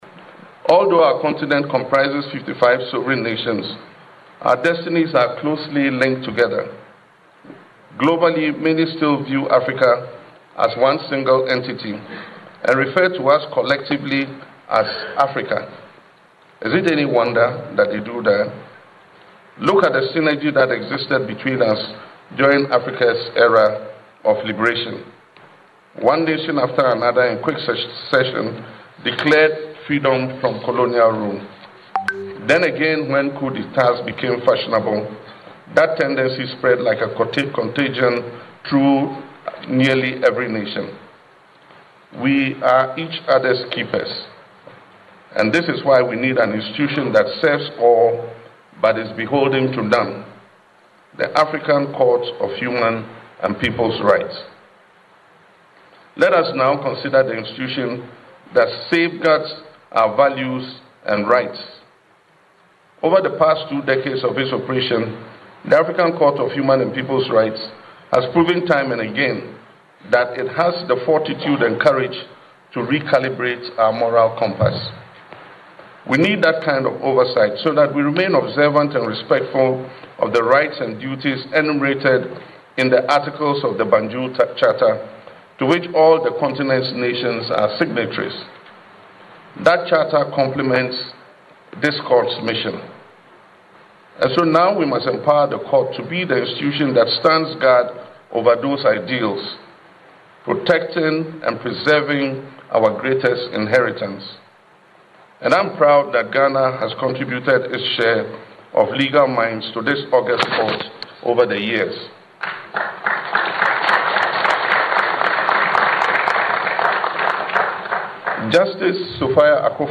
Delivering an address at the anniversary ceremony of the African Court on Human and Peoples’ Rights in Arusha, President Mahama acknowledged Africa’s vast natural resource endowment.